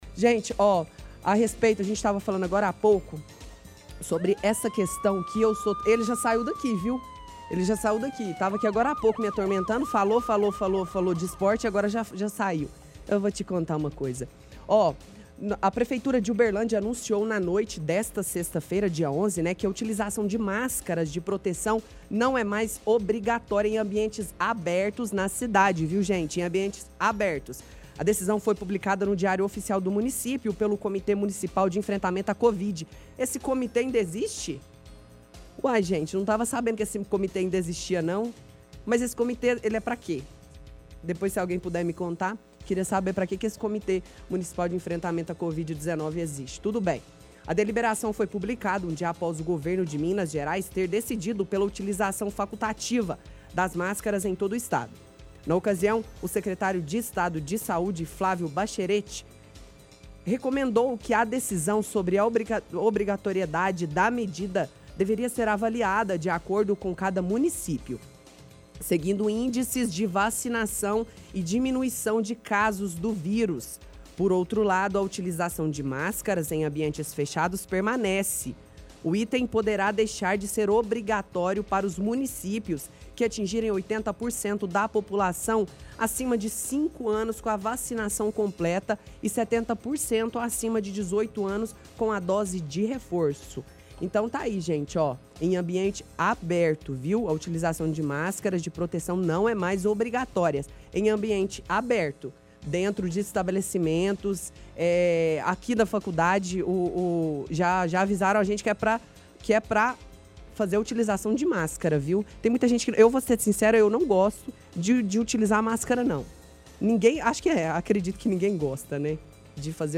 – Debocha ao falar do comitê de enfrentamento: “Esse comitê existe?”.